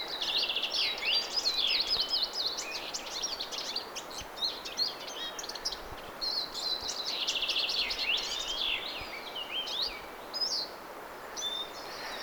Ja laulu on aika miellyttävän kuuloistakin.
Sävy on melodinen voi kai sanoa?
pieni pätkä oranssipääkertun laulua
pieni_patka_oranssikertun_laulua.mp3